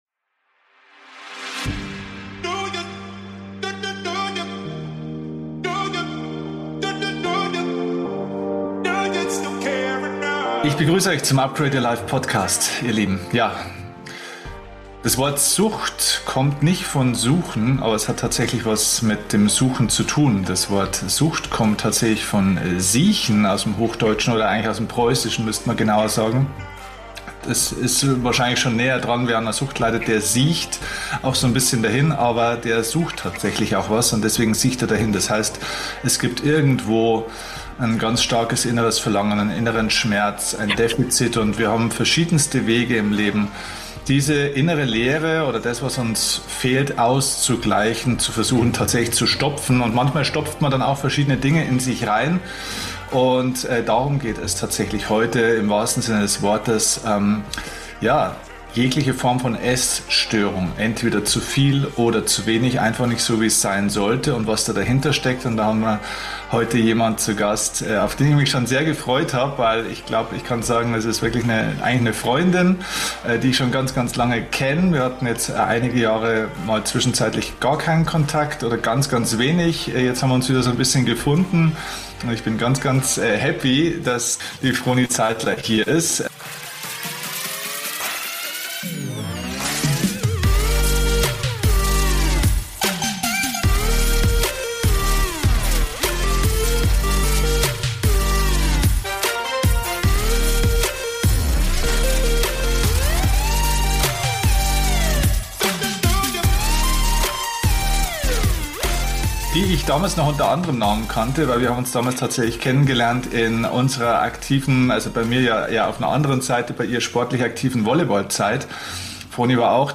#437 Essstörung adé - Endlich wohlfühlen im eigenen Körper – Interview